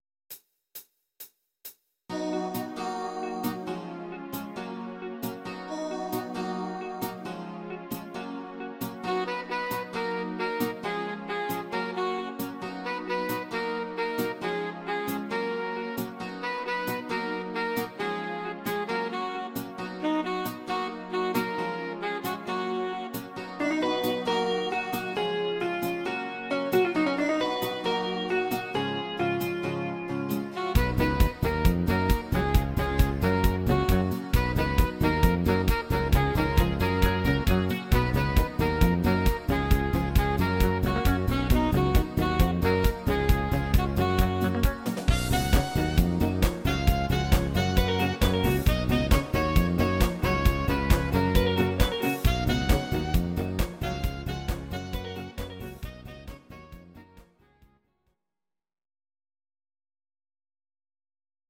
Audio Recordings based on Midi-files
Pop, 2010s